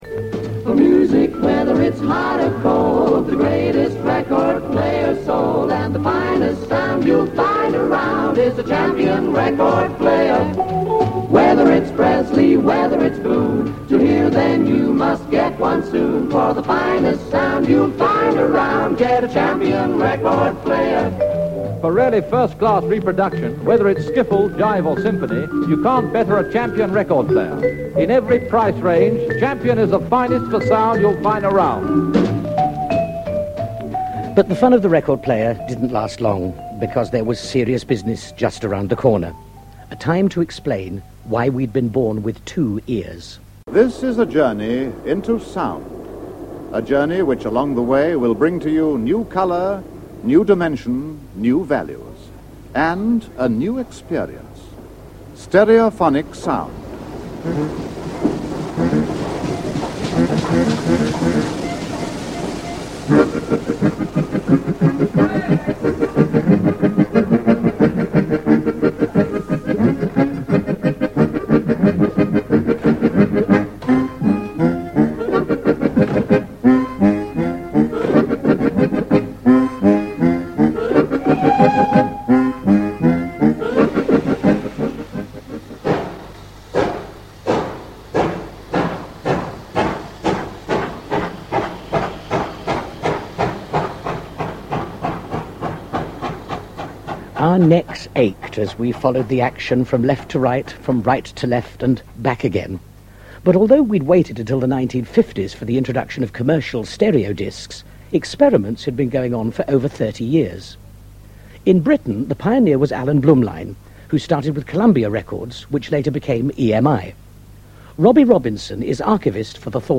Broadcast on Radio 4 in 1988.